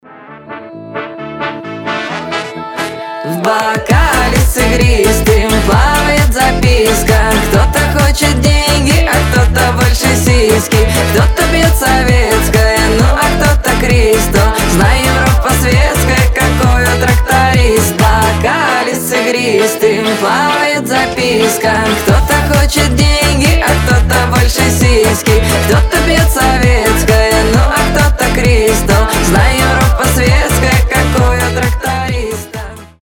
веселые
попса